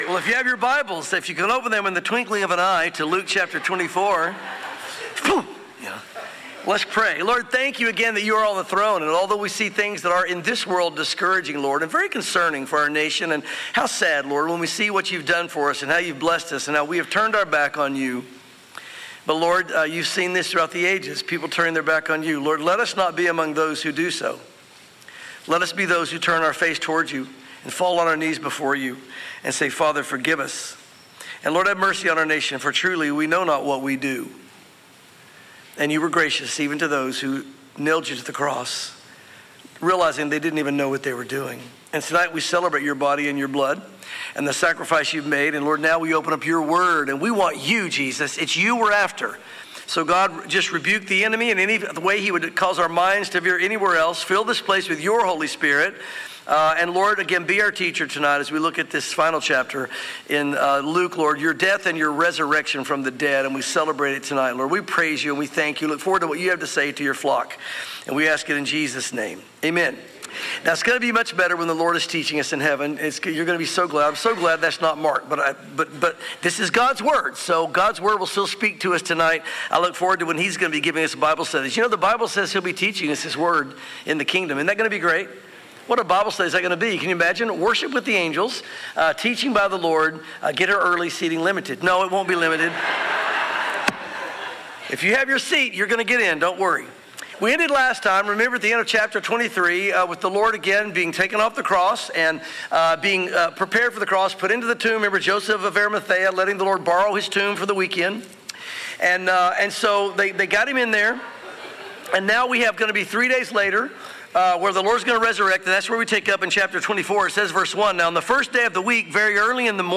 sermons Luke 24